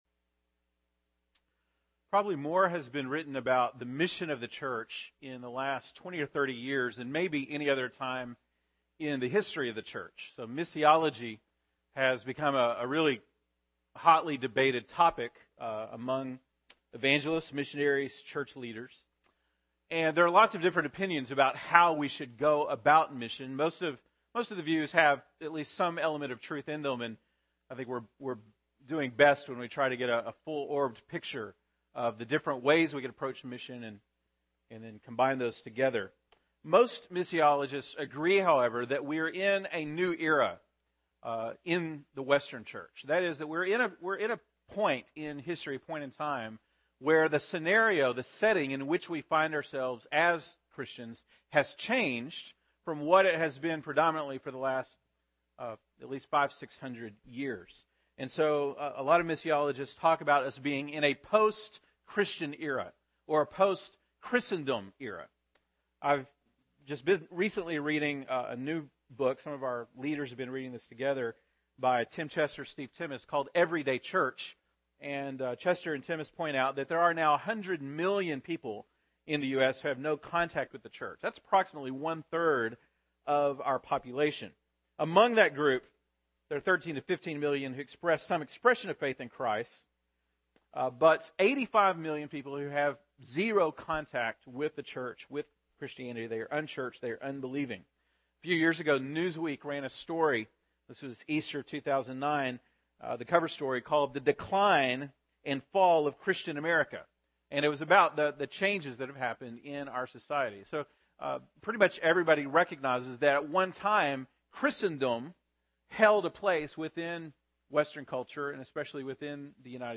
March 17, 2013 (Sunday Morning)